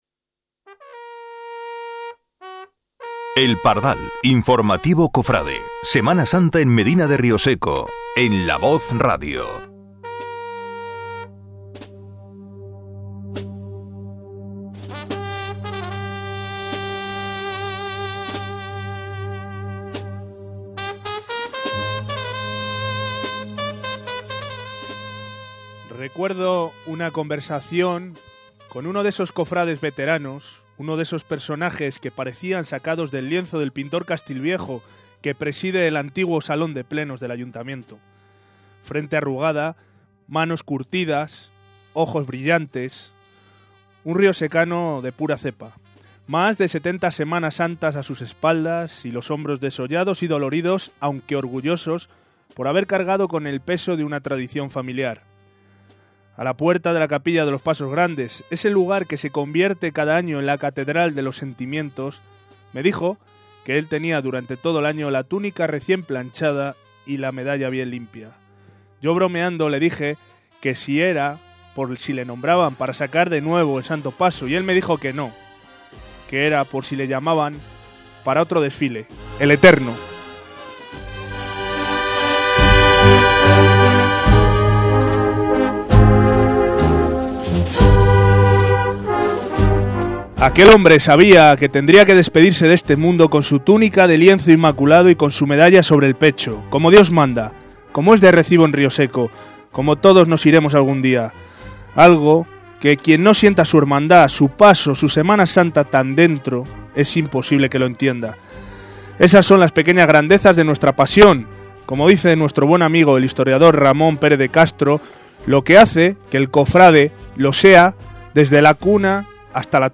Informativo Cofrade de la Cuaresma.